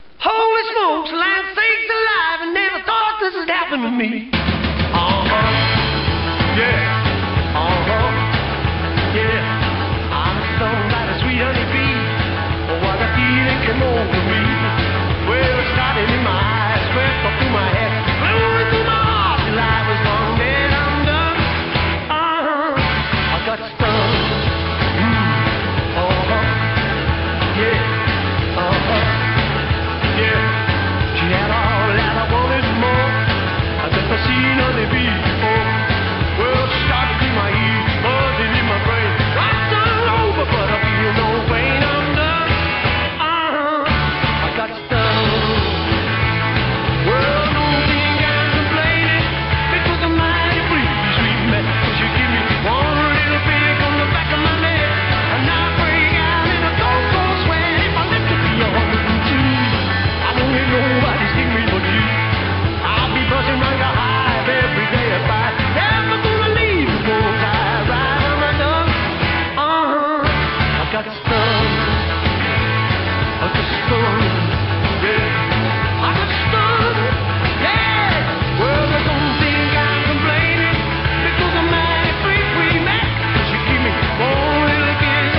O instrumental está ótimo
um rock mesmo.